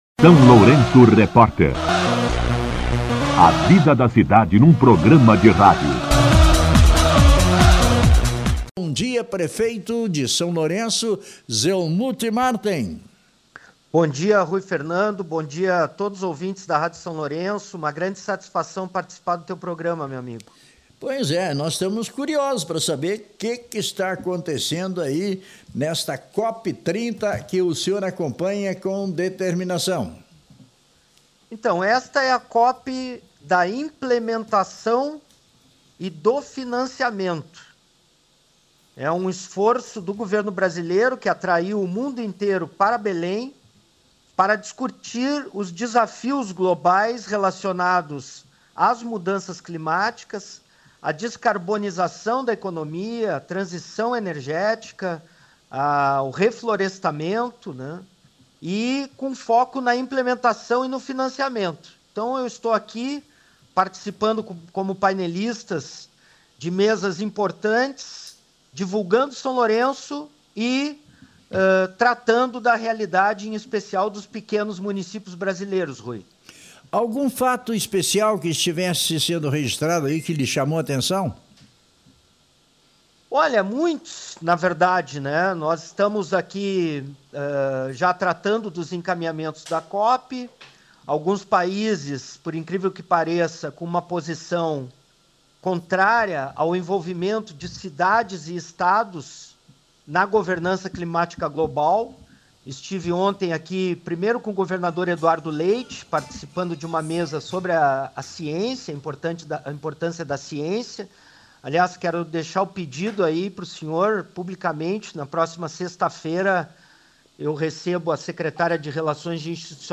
O SLR RÁDIO conversou com o prefeito Zelmute Marten direto de Belém do Pará, onde ocorre a COP 30 – Conferência das Partes da Convenção-Quadro das Nações Unidas sobre Mudança do Clima, conferência global que reúne quase 200 países em torno das ações contra as mudanças climáticas.
Entrevista com o prefeito Zelmute Marten